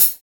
HARD CHH 2.wav